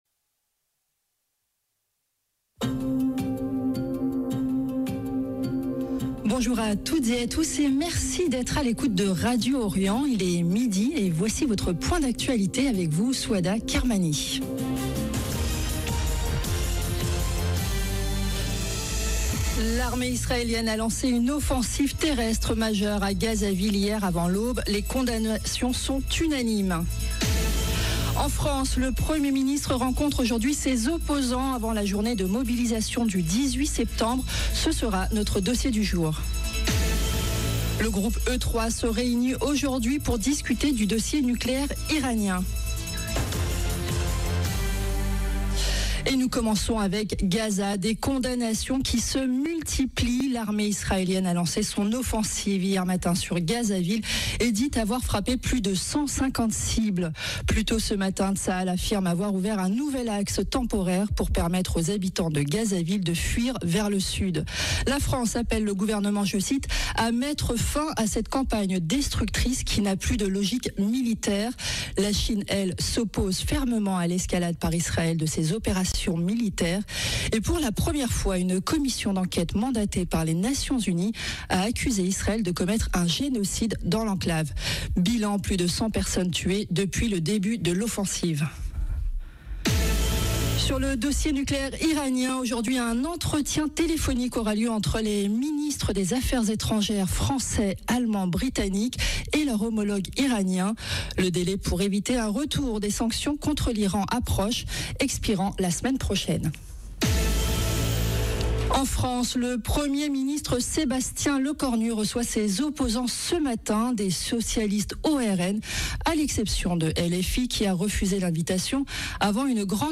Journal de midi du 17 septembre 2025